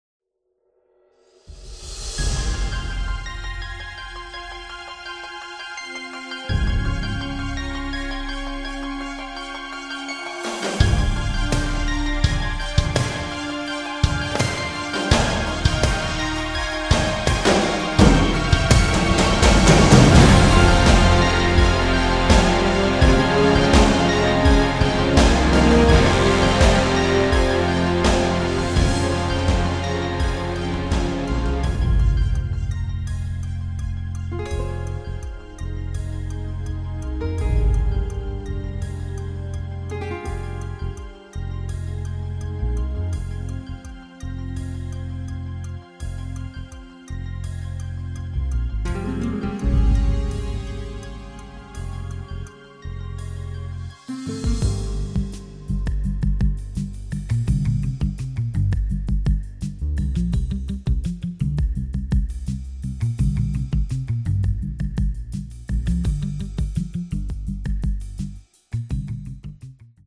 SCORE PRESENTATION